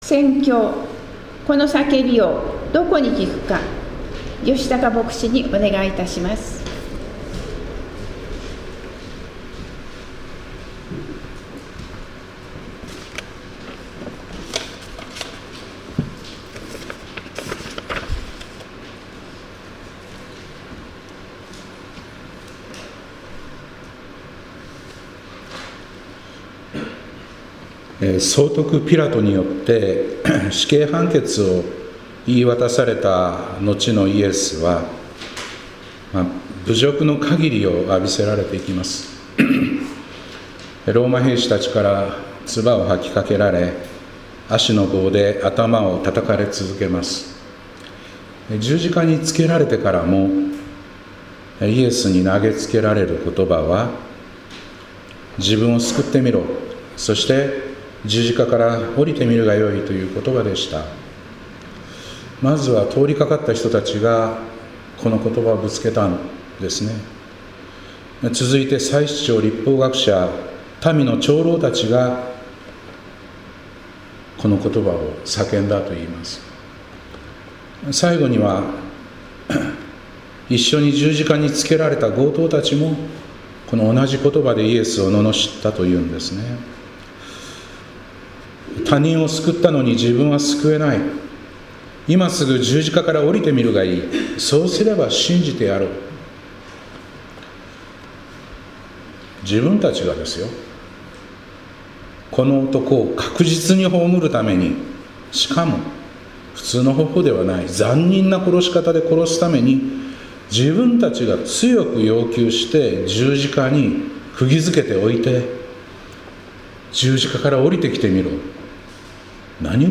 2025年4月13日礼拝「この叫びをどこに聞くか」